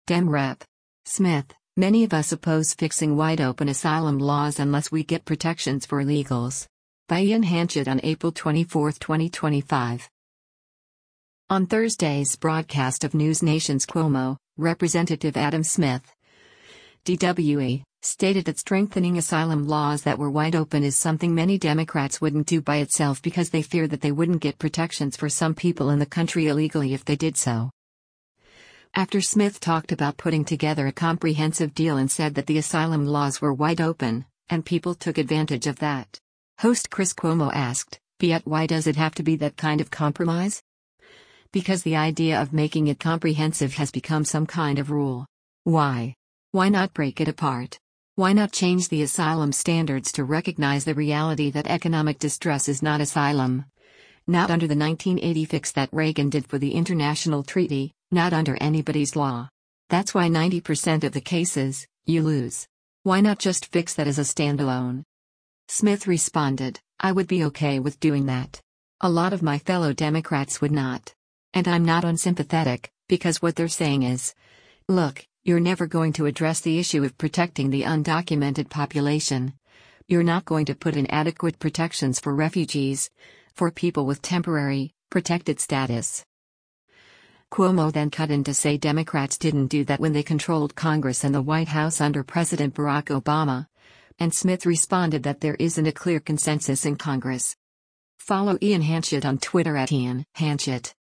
On Thursday’s broadcast of NewsNation’s “Cuomo,” Rep. Adam Smith (D-WA) stated that strengthening asylum laws that were “wide open” is something many Democrats wouldn’t do by itself because they fear that they wouldn’t get protections for some people in the country illegally if they did so.